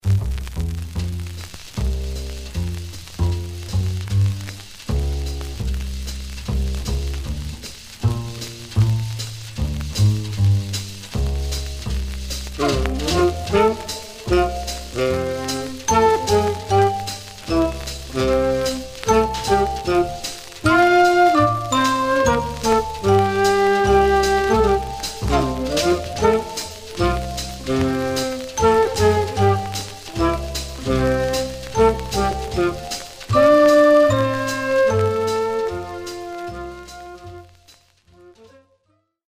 Stereo/mono Mono
Jazz